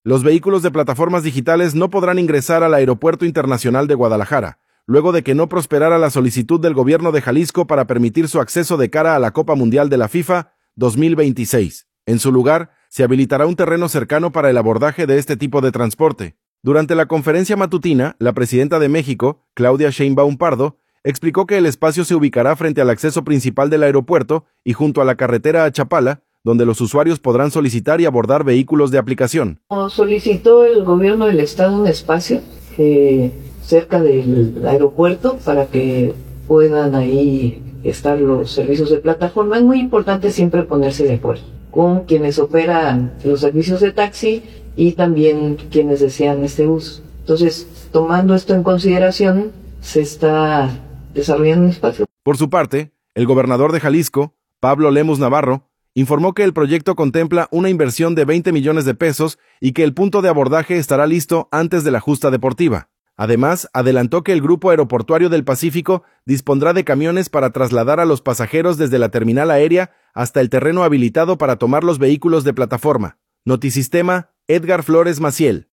Durante la conferencia matutina, la presidenta de México, Claudia Sheinbaum Pardo, explicó que el espacio se ubicará frente al acceso principal del aeropuerto y junto a la carretera a Chapala, donde los usuarios podrán solicitar y abordar vehículos de aplicación.